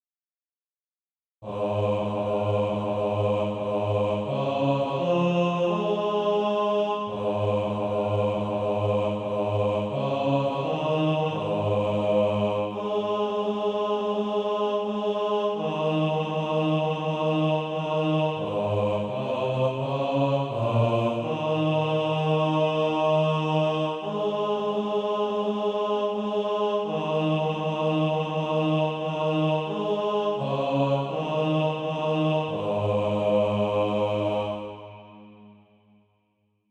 Bass Track.